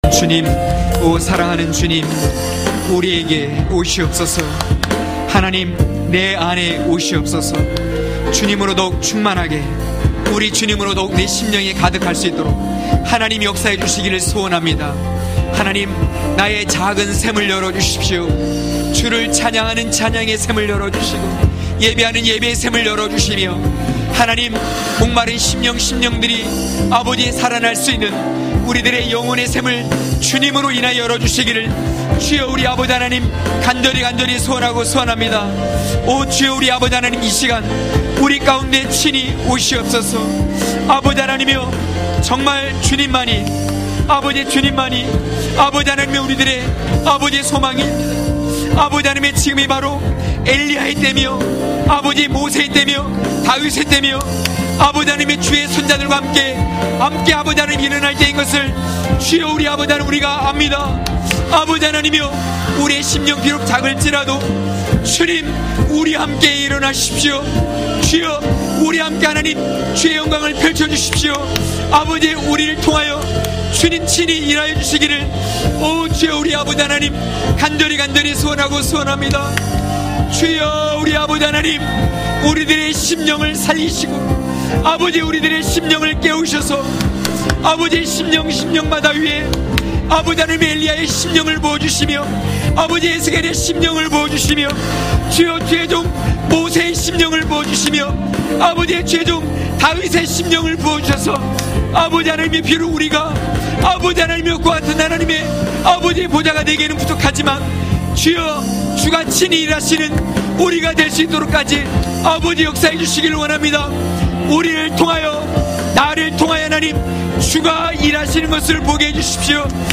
강해설교 - 5.느헤미야 총독과 대제사장 엘리아십(느3장1~9절).mp3